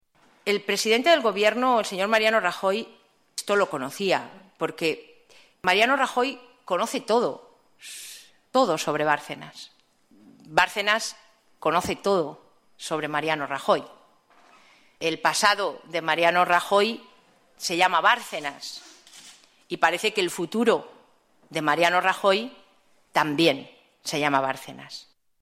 Soraya Rodríguez en rueda de prensa el 22/2/2013 denuncia que Rajoy sabe todo lo que hizo Bárcenas